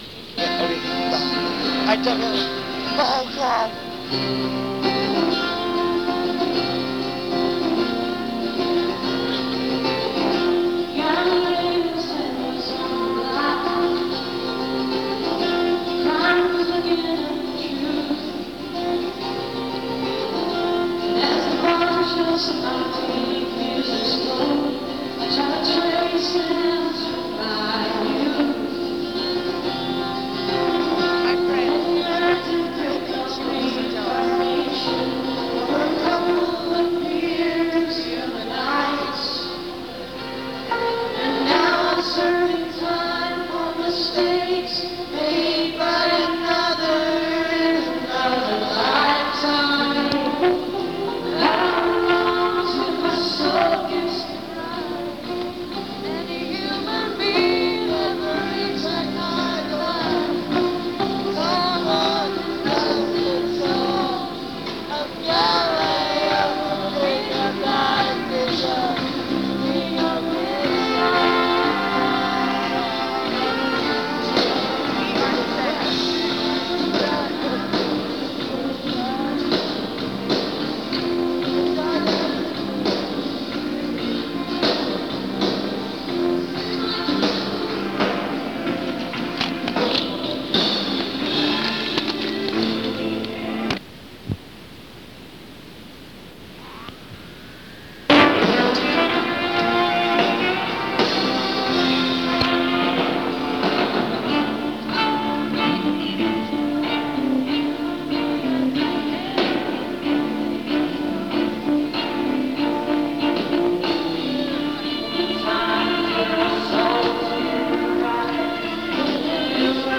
soundcheck